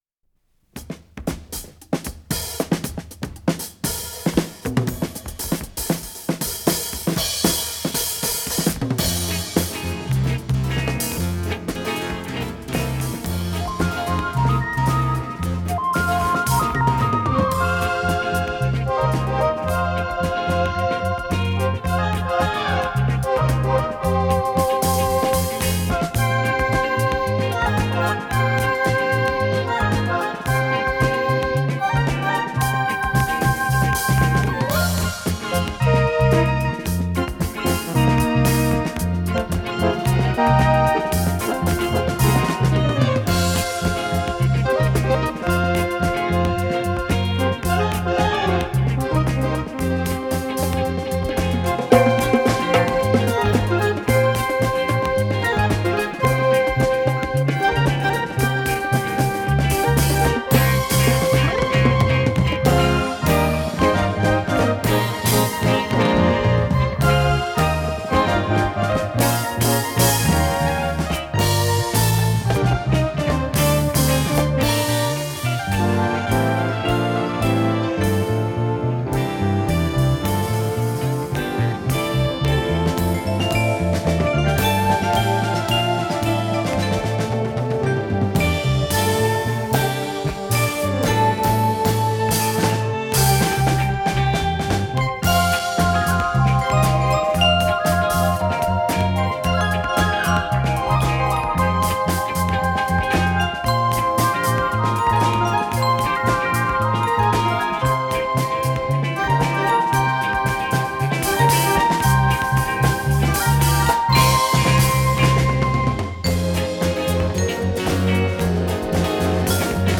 с профессиональной магнитной ленты
ПодзаголовокПьеса для инструментального ансамбля, фа минор
ВариантДубль моно